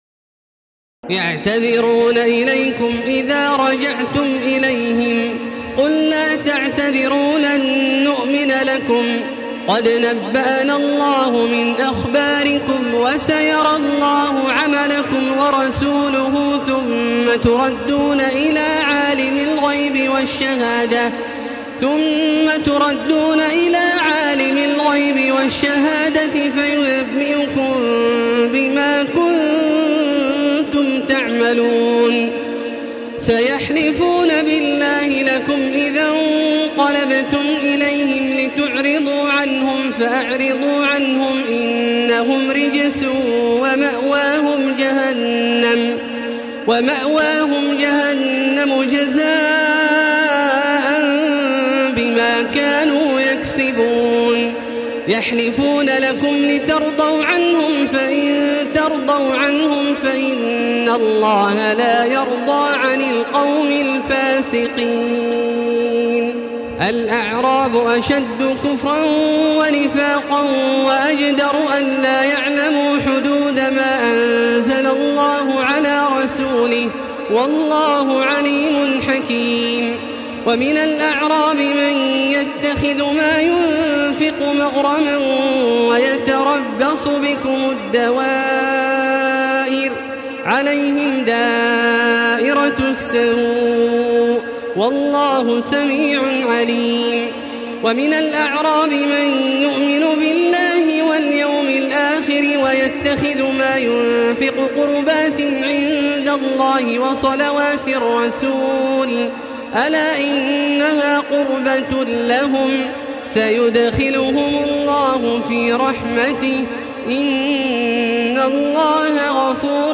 تراويح الليلة الحادية عشر رمضان 1435هـ من سورتي التوبة (94-129) و يونس (1-25) Taraweeh 11 st night Ramadan 1435H from Surah At-Tawba and Yunus > تراويح الحرم المكي عام 1435 🕋 > التراويح - تلاوات الحرمين